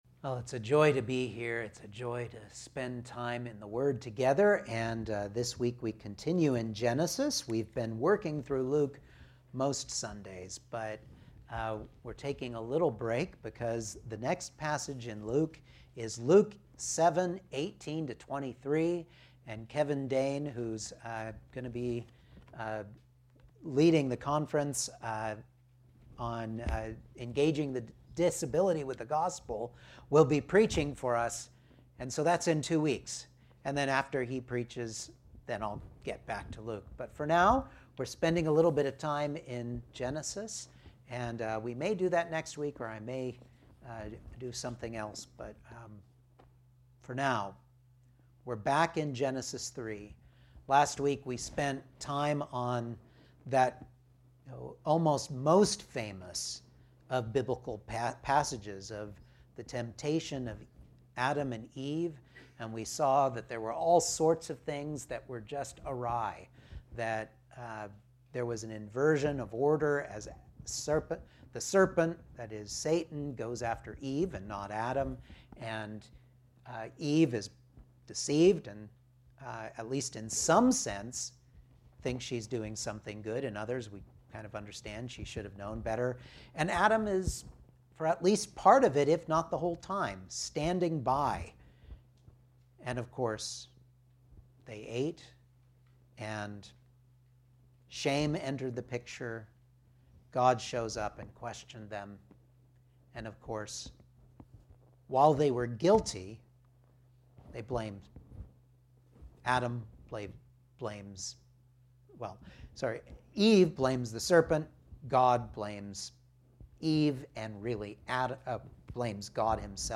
Genesis 3:14-24 Service Type: Sunday Morning Outline